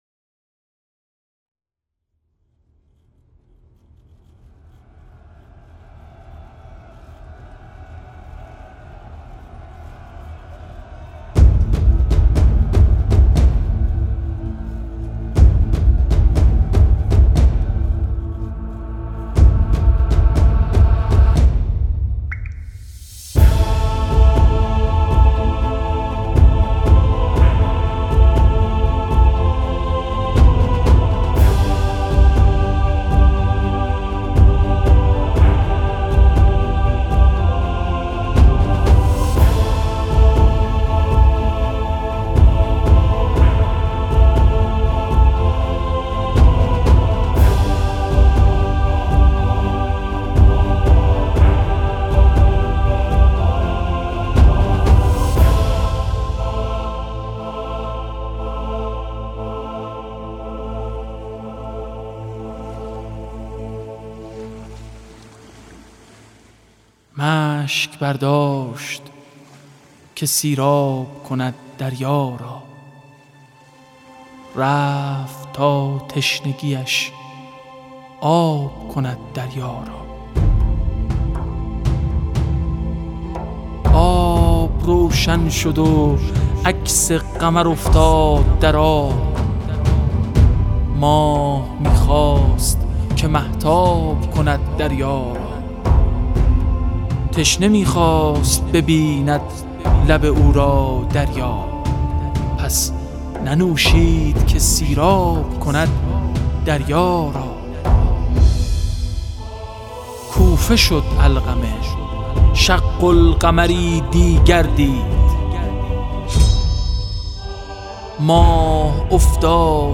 تحریرهای رود ، شعر عاشورایی